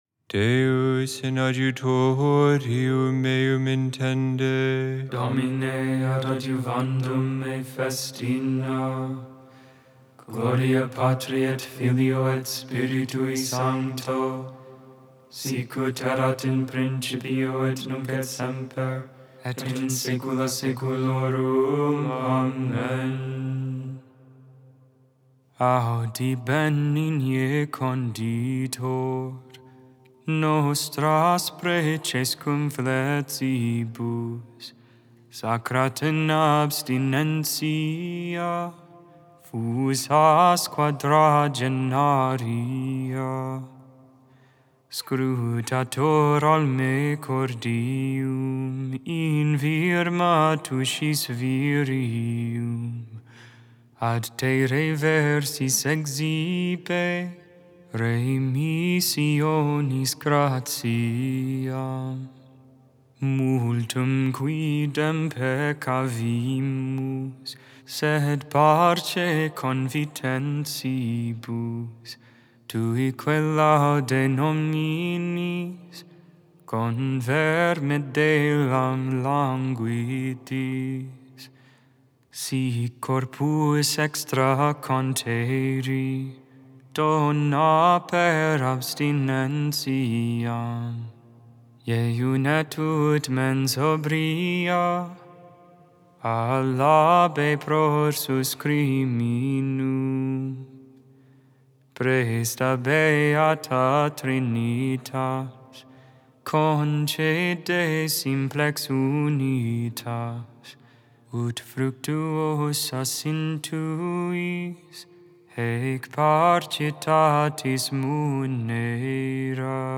4.5.25 Vespers I, Saturday Evening Prayer of the Liturgy of the Hours